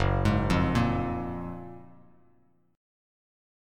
GbM9 chord